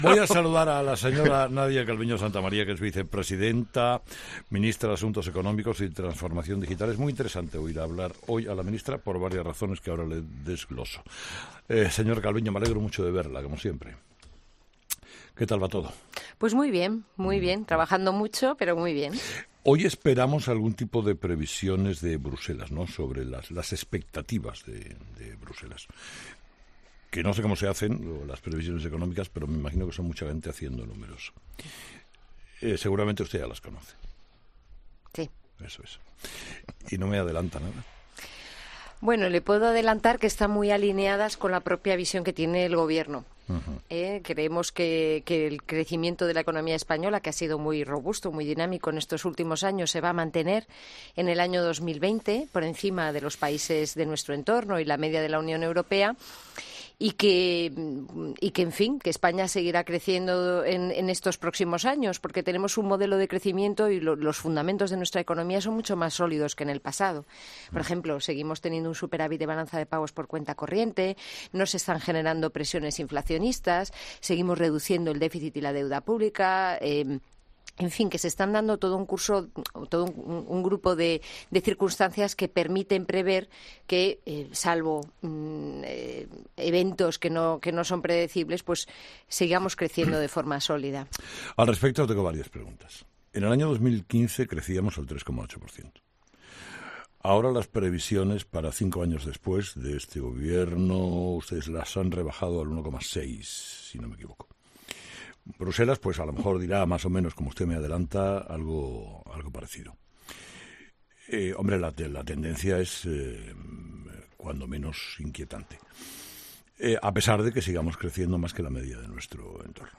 En una entrevista este jueves en "Herrera en COPE", la vicepresidenta ha asegurado que no existe ninguna razón que impida celebrar este tipo de eventos en nuestro país.